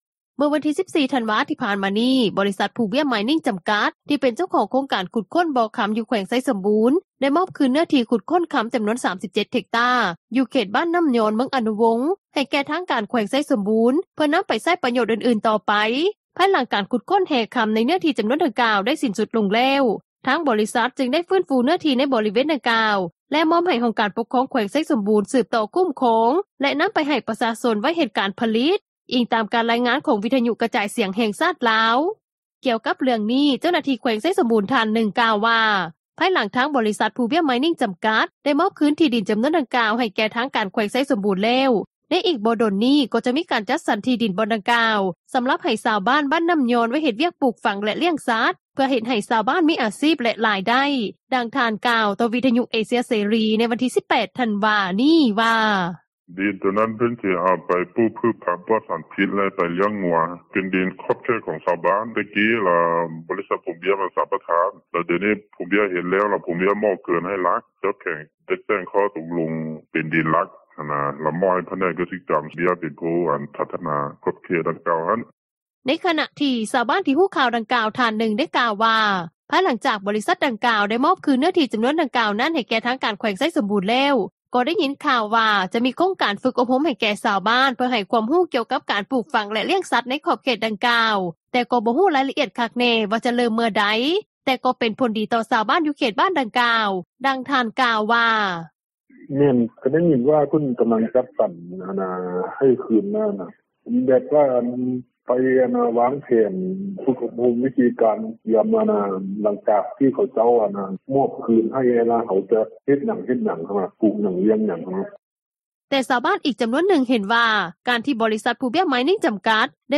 ດັ່ງທ່ານ ກ່າວວ່າ:
ດັ່ງຊາວບ້ານ ທ່ານນຶ່ງ ກ່າວວ່າ:
ດັ່ງຜູ້ປະກອບການທ່ອງທ່ຽວ ນາງນຶ່ງ ກ່າວວ່າ: